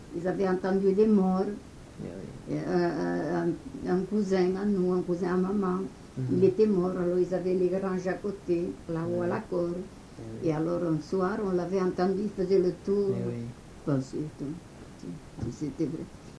Aire culturelle : Couserans
Genre : récit de vie